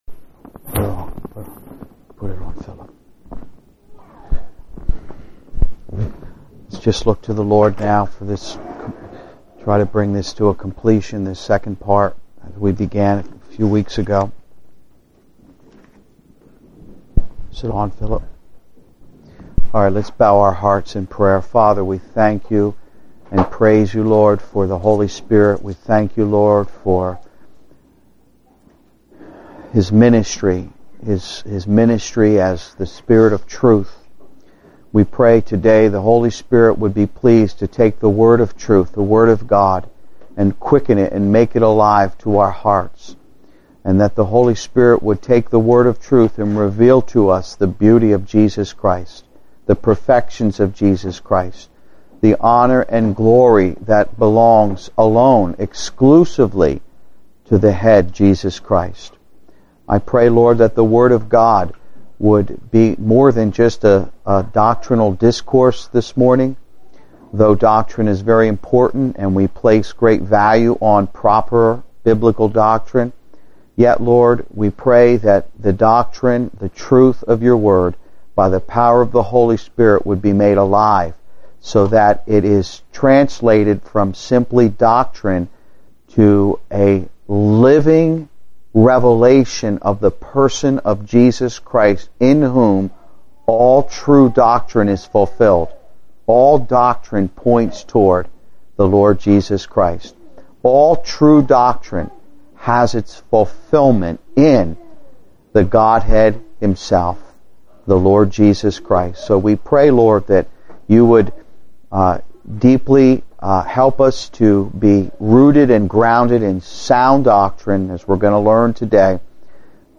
The sermon stresses the importance of understanding our positional standing in Christ and how it should manifest in our daily lives through the Holy Spirit.